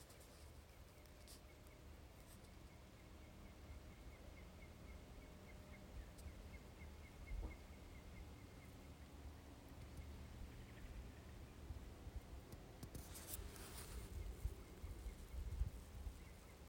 Dienas plēsīgais putns (nenoteikts), Accipitriformes/Falconiformes sp.
StatussDzirdēta balss, saucieni